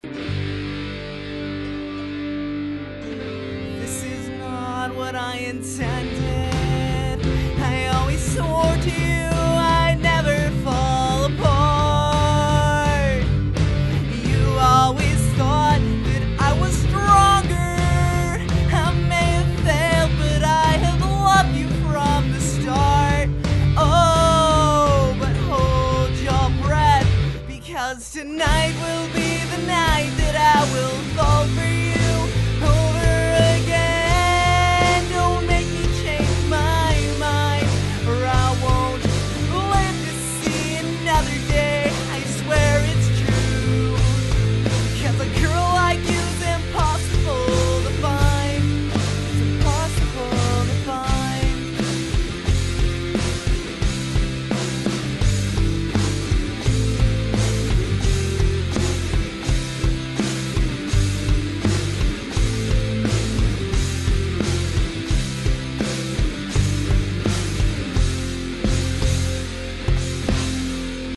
Also i didn't add reverb or delay.
P.S. I am fully aware i am a whiny singer.